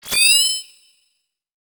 Holographic UI Sounds 9.wav